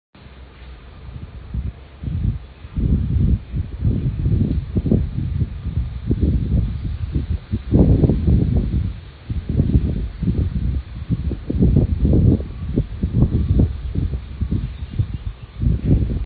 內湖區環山路一段圖書二館巷弄
均能音量: 53.6 dBA 最大音量: 72.8 dBA
寧靜程度: 5分 (1分 – 非常不寧靜，5分 – 非常寧靜)
聲音類型: 戶外、鄉村或自然、風、沙沙作響的樹葉